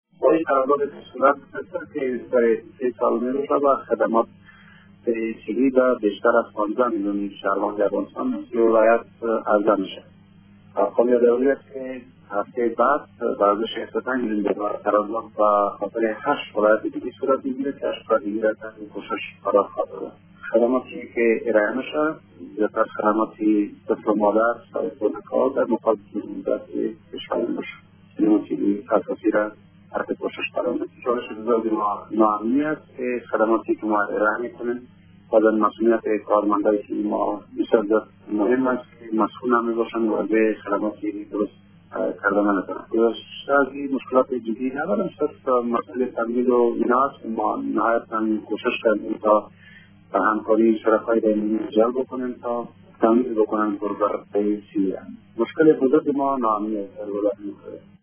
در گفت گو با برنامه انعکاس رادیو دری